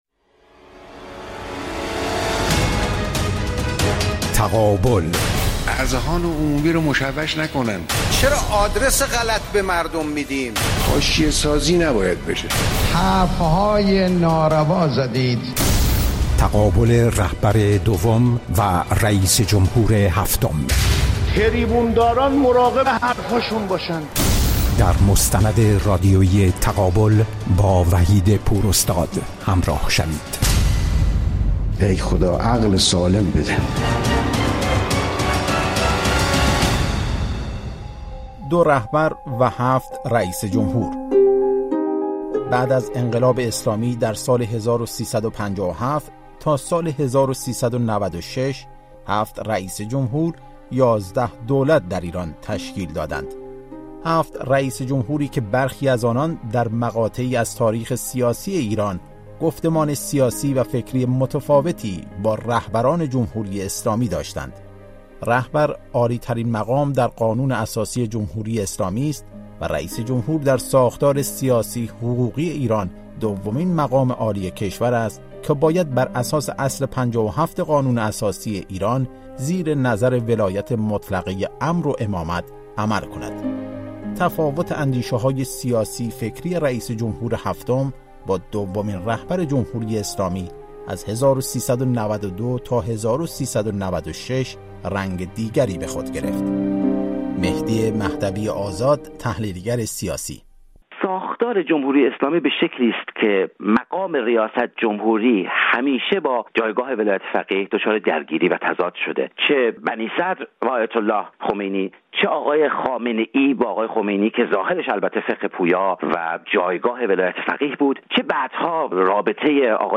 مستند رادیویی تقابل/ بخش اول